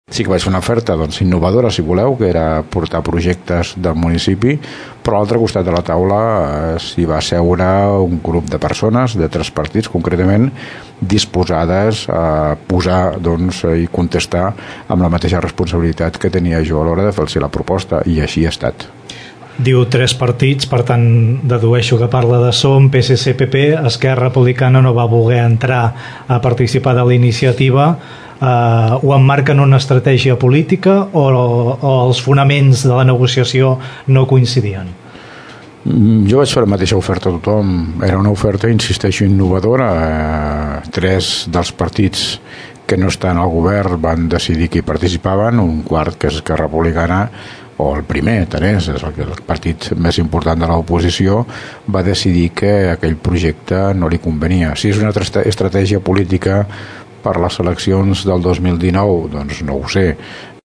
entrevista alcaldeDarrera valoració d’aquest primera meitat de legislatura amb l’alcalde de Tordera, Joan Carles Garcia del PDeCAT.